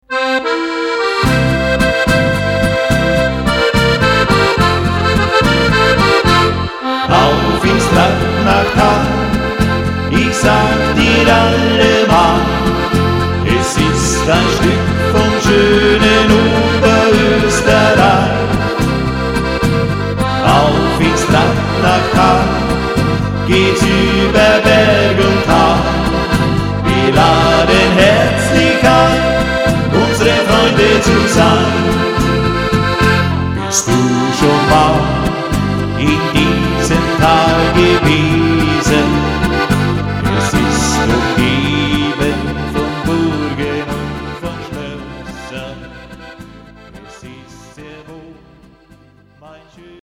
Volksmusik/Schlager